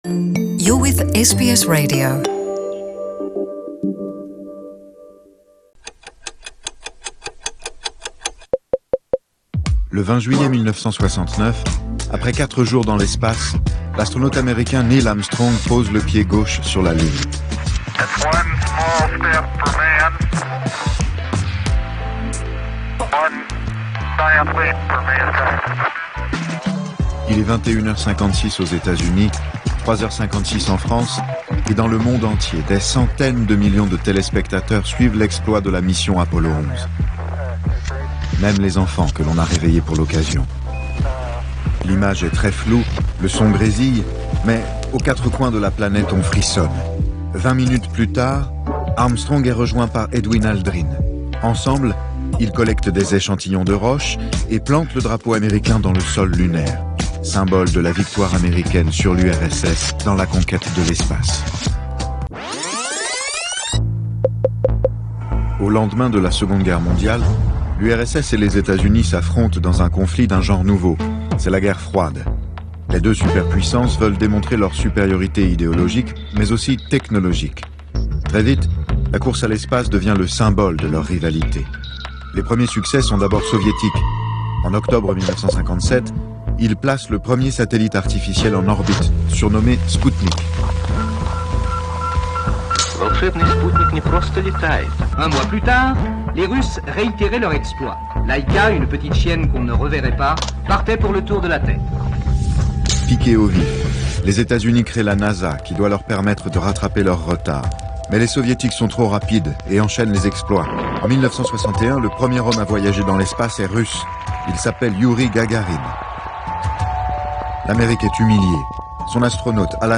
Dans la nuit du 20 au 21 juillet 1969, l'Américain Neil Armstrong devenait le premier homme à marcher sur la Lune. Retour sur cet événement avec un reportage coproduit par l'INA, France TV Éducation, TV5 Monde et la RTS.